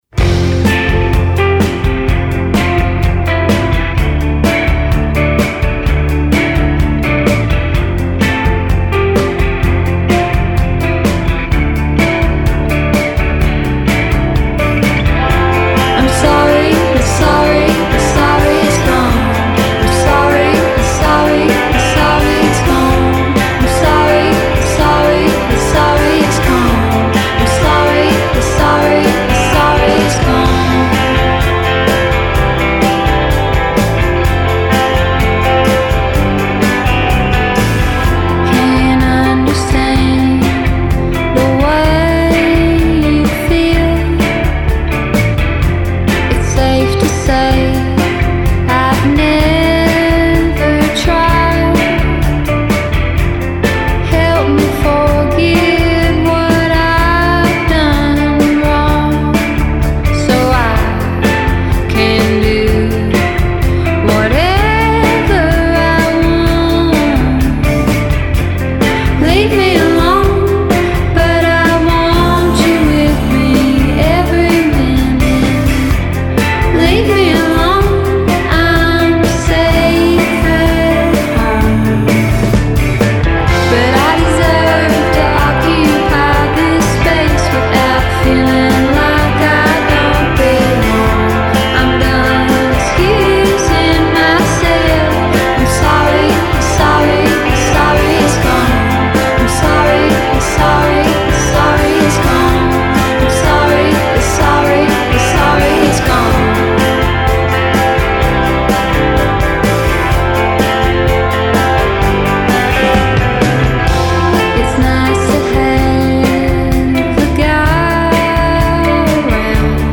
distinctive alt-country sound
is the brightest, most unapologetic song on the album.